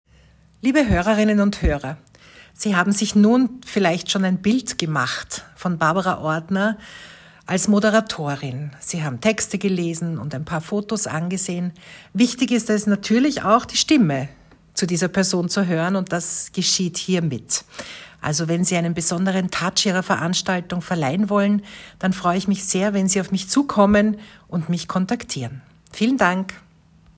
Moderation
Sprechprobe